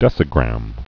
(dĕsĭ-grăm)